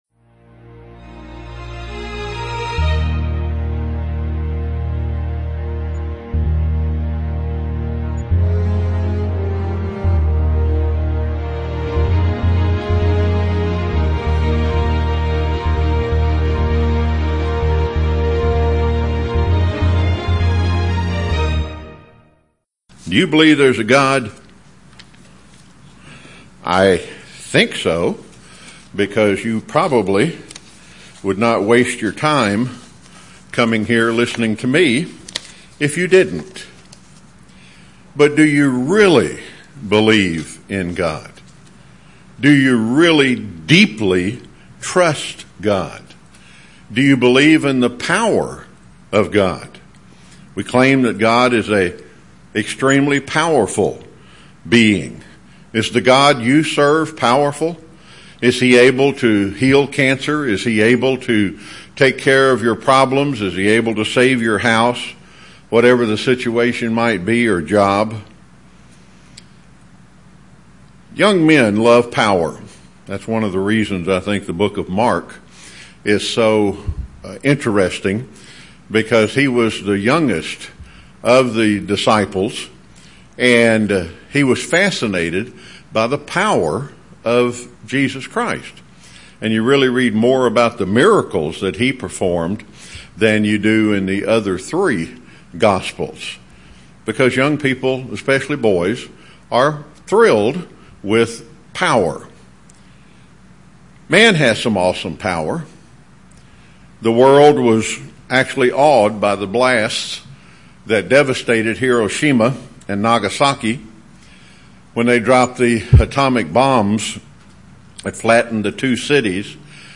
Sermon: The Power of the God you serve!!!